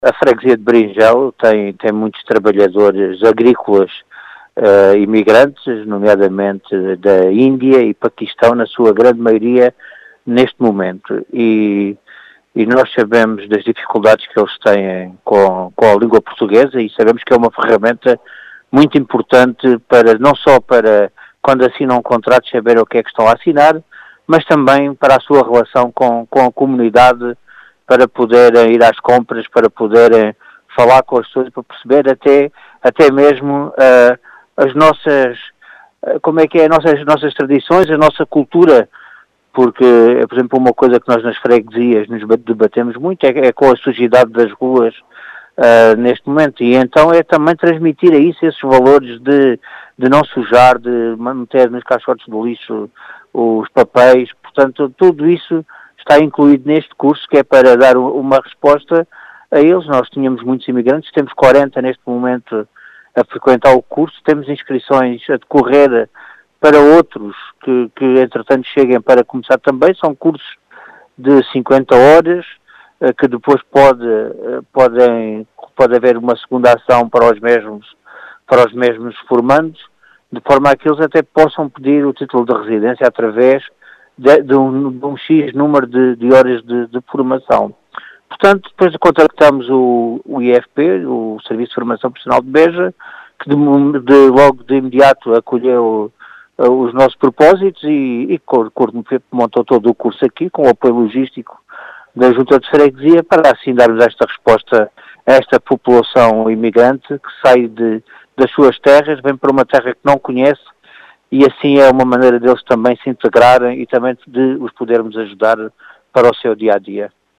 As explicações foram deixadas por Vítor Besugo, presidente da junta de freguesia de Beringel, que realçou a importância destes cursos para esta comunidade imigrante, de maioria do Paquistão e India, na integração e interação na freguesia.